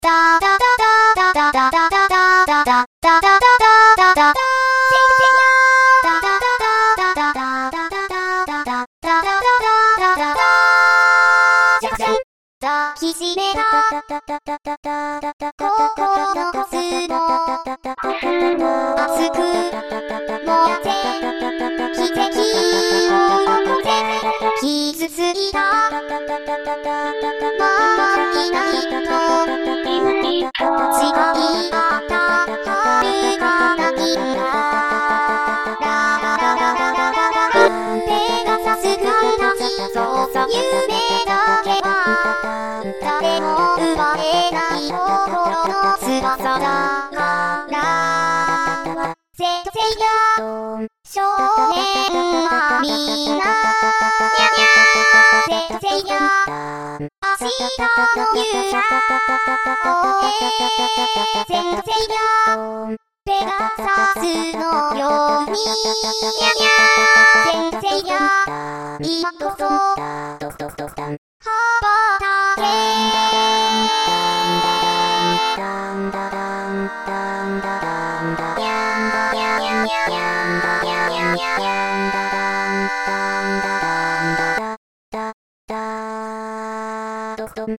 先にＵＰした方は歌の部分だけでしたけど、実は前奏から作っていました…
ボーカル
コーラス
ベース